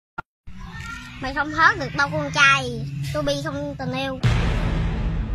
Thể loại: Câu nói Viral Việt Nam
Đoạn video có chút hài hước, dí dỏm tạo tiếng cười cho người xem.